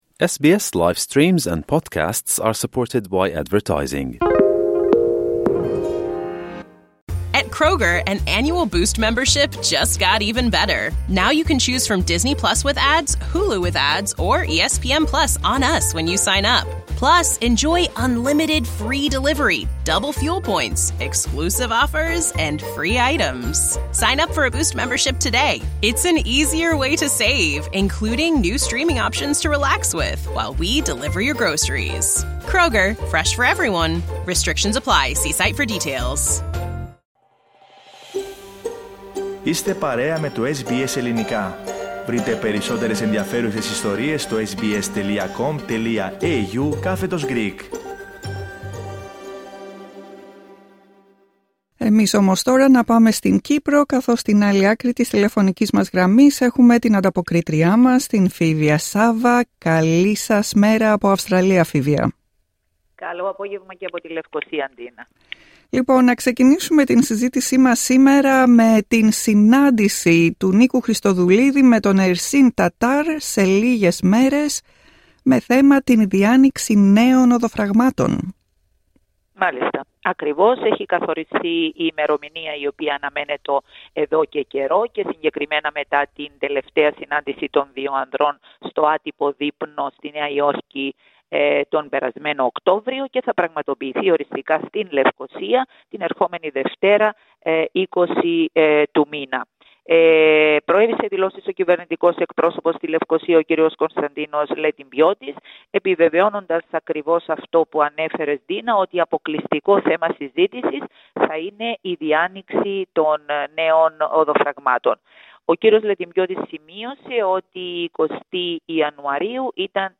" Ελπίζω και ο κύριος Τατάρ να έρθει με την ίδια διάθεση και να μπορούμε να ανακοινώσουμε αποτελέσματα που θα είναι προς όφελος του κυπριακού λαού στο σύνολό του” Ακούστε τα υπόλοιπα θέματα της ανταπόκρισης από την Κύπρο, πατώντας PLAY δίπλα από την κεντρική φωτογραφία.